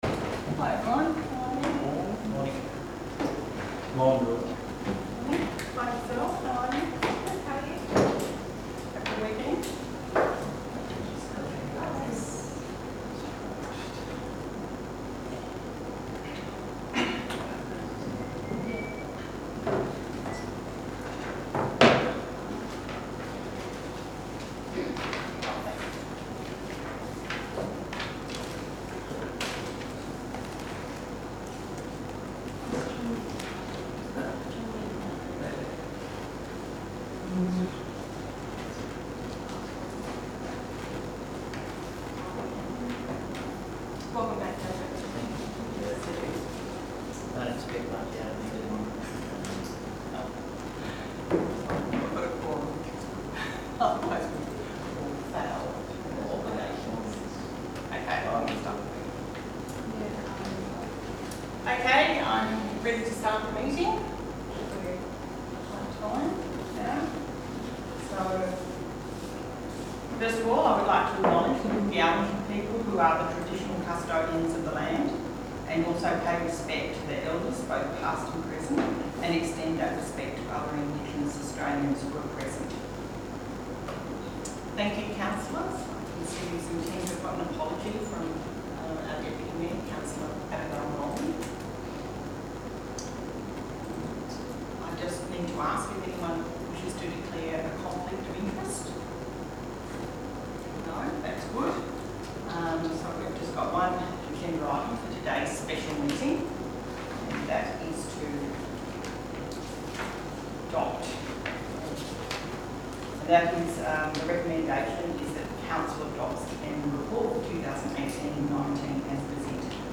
Download 11 November 2019 Special Meeting Audio Recording